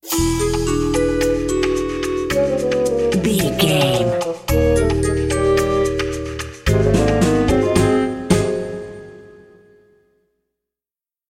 Aeolian/Minor
C#
percussion
flute
bass guitar
circus
goofy
comical
perky
Light hearted
quirky